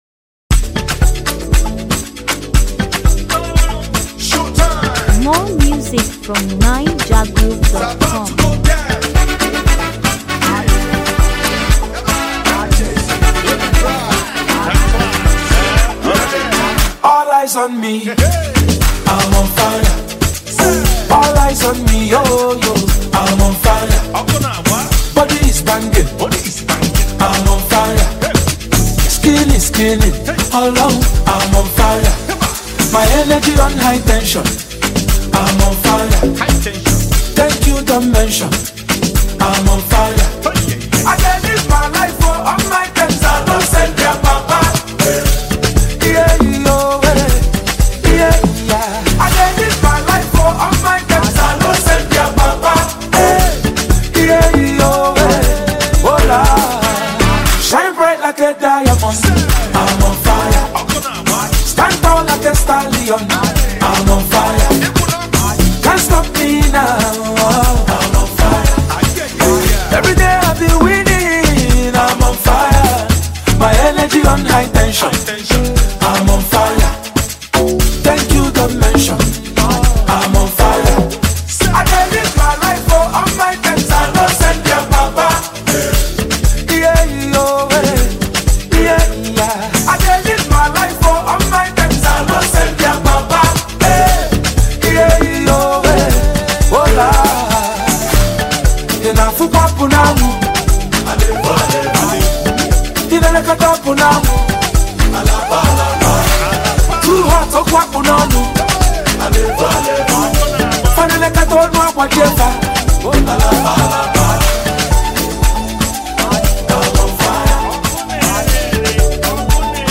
warm, textured record